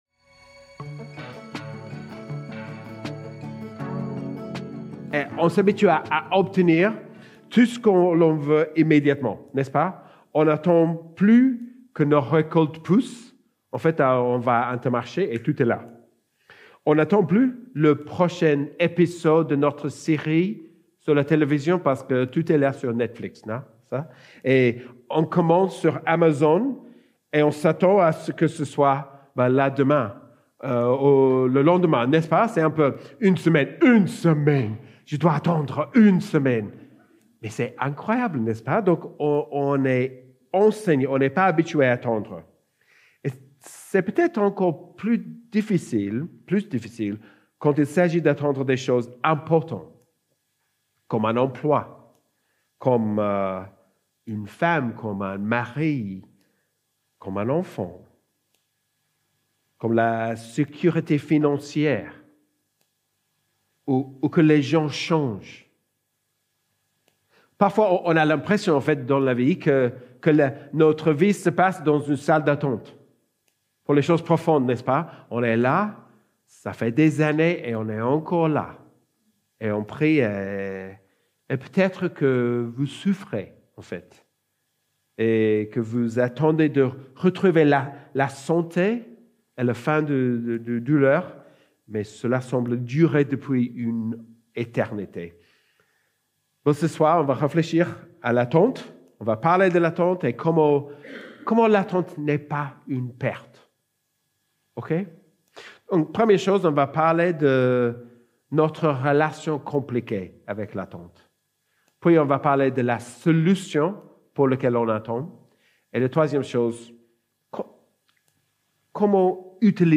Predication12-1.mp3